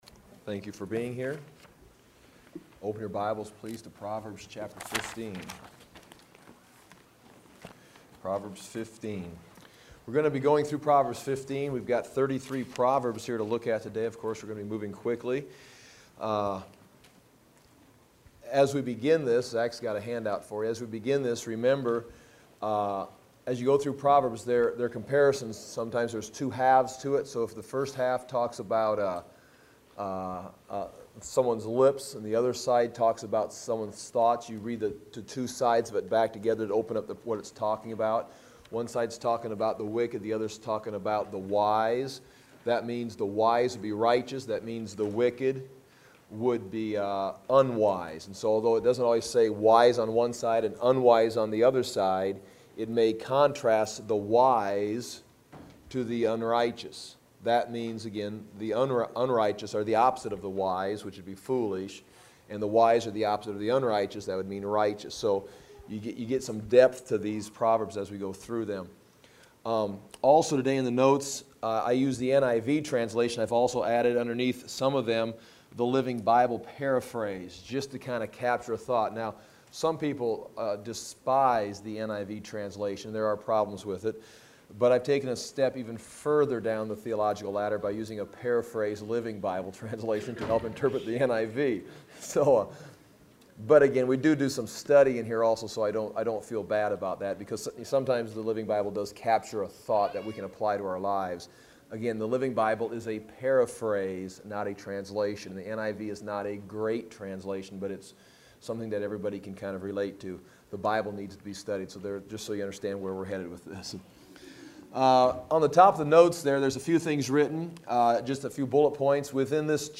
The book of Proverbs is taught during a series of Sunday church services. Proverbs provides eternal wisdom for our lives today.